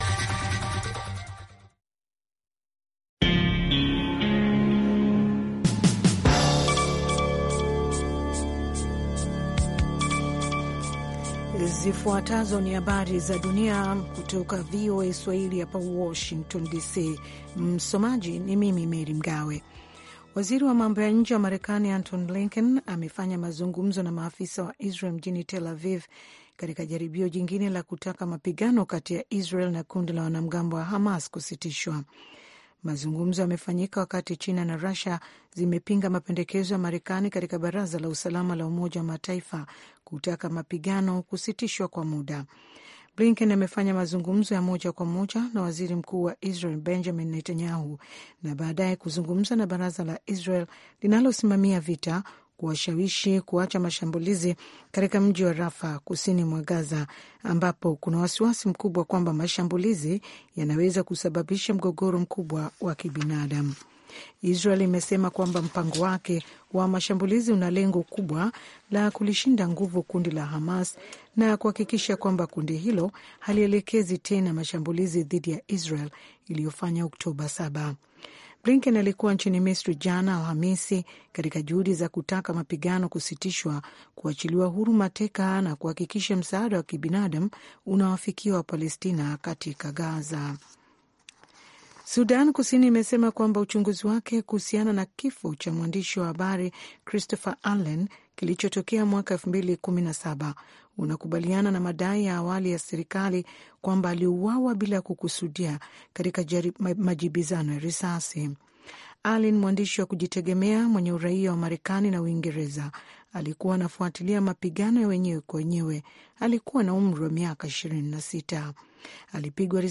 Meza ya waandishi wa habari ikiangazia matukio muhimu ya wiki, miongoni ukiwemo uchaguzi mkuu wa Senegal.
Kwa Undani ni matangazo ya dakika 25 yanayochambua habari kwa undani zaidi na kumpa msikilizaji maelezo ya kina kuliko ilivyo kawaida kuhusu tukio au swala lililojitokeza katika habari.